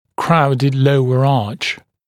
[‘kraudɪd ‘ləuə ɑːʧ][‘краудид ‘лоуэ а:ч]нижняя зубная дуга, в которой имеется скученность; скученность нижней зубной дуги